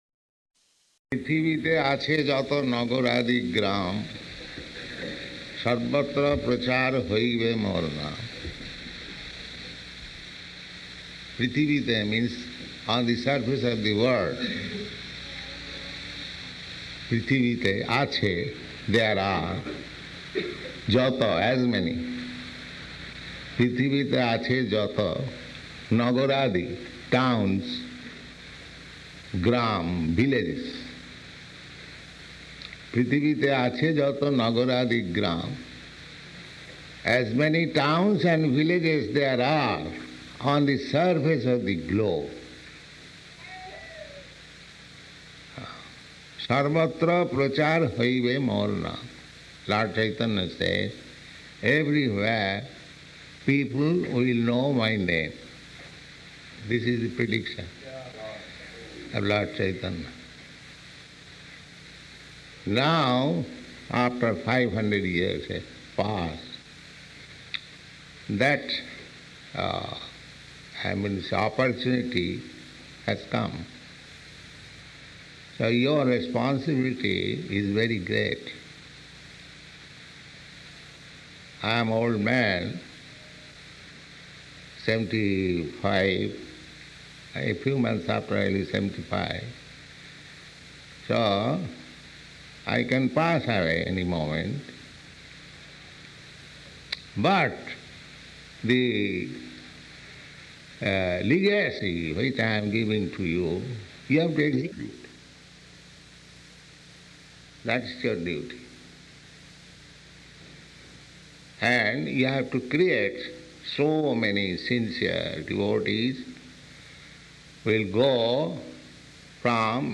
Location: Los Angeles